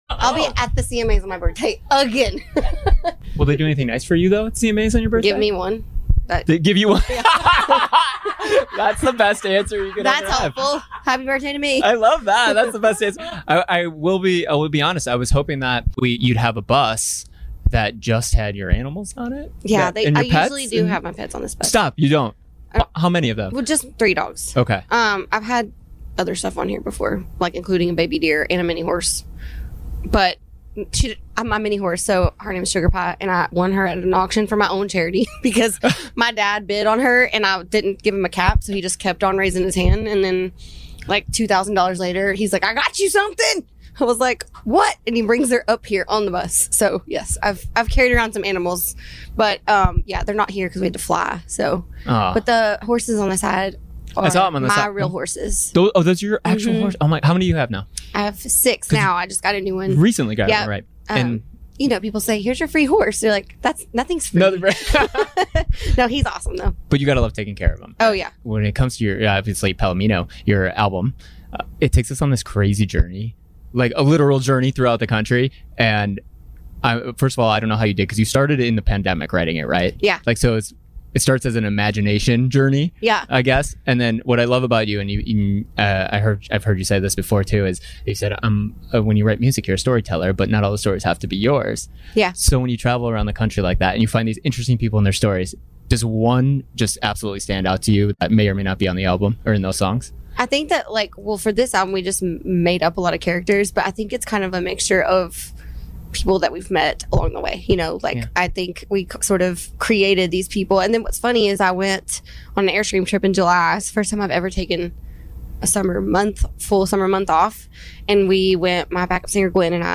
We climbed aboard Miranda Lambert's bus at the Windy City Smokeout to talk animals, finding balance, music and more.